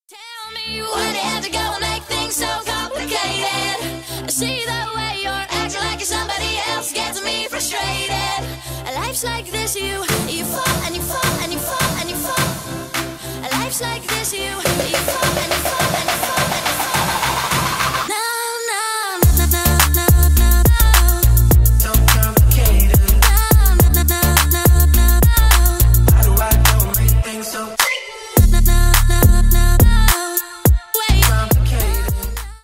Ремикс